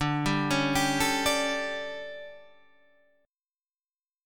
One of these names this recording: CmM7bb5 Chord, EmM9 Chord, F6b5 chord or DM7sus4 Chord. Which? DM7sus4 Chord